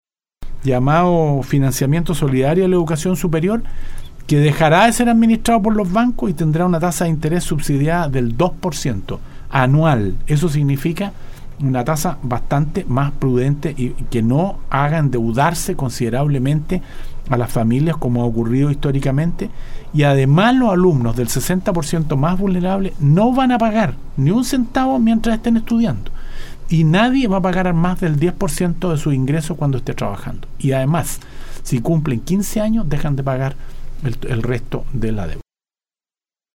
El Ministro de Minería, Baldo Prokurica estuvo en los estudios de Nostálgica, donde pudo profundizar en la reciente presentación que efectuó el Presidente de la República, del programa llamado Clase Media Protegida.